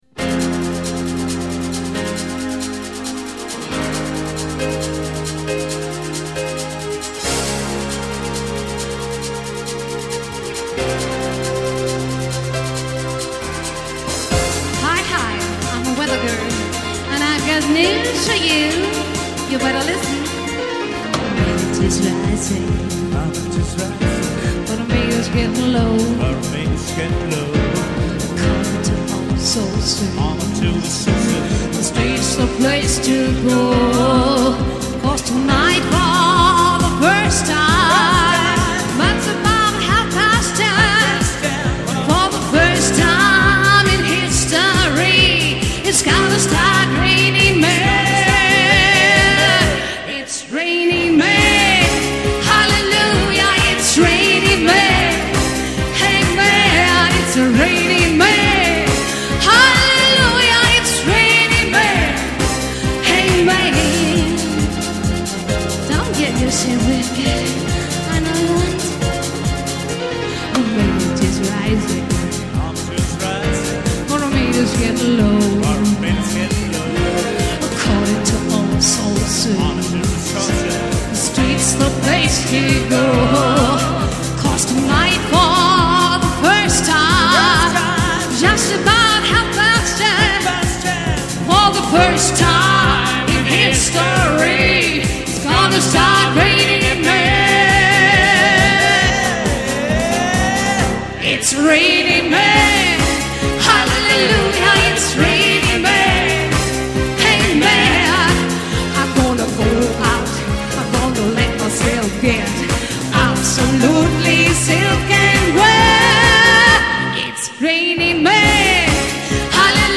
Fete und Coverband
• Allround Partyband